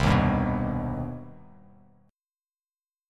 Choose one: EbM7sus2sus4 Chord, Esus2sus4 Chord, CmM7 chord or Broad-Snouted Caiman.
CmM7 chord